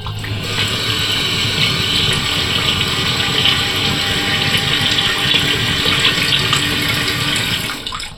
Vaciado completo de una cisterna en una pared en unos urinarios de un bar